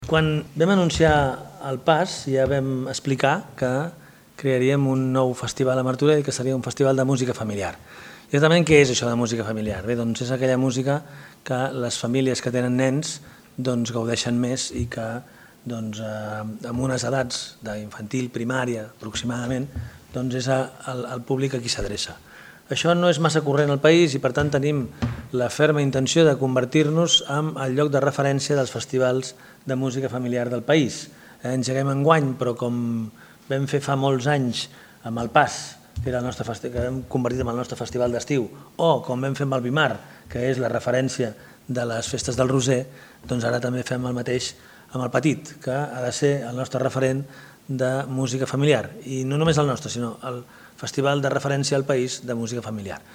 Presentació El Petit
Xavier Fonollosa, alcalde de Martorell